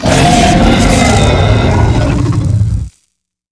droid
bighurt3.wav